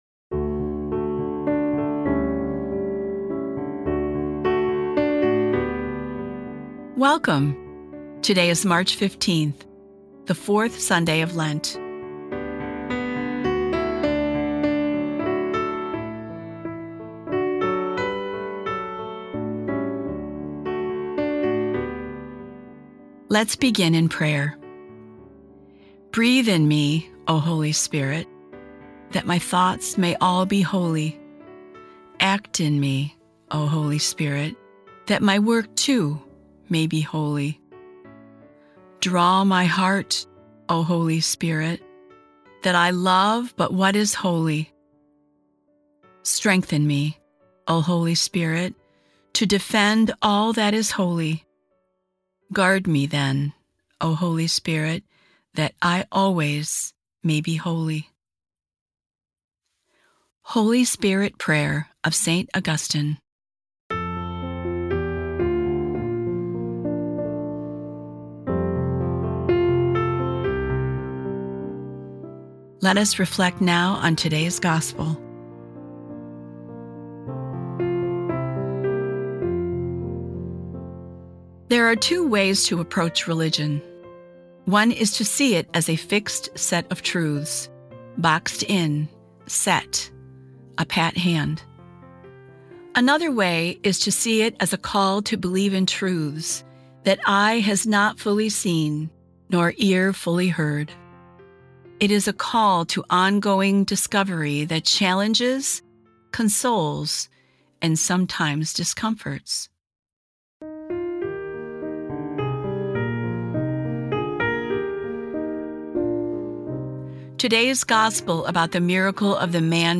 Today's episode of Sundays with Bishop Ken is a reading from The Little Black Book: Lent 2026.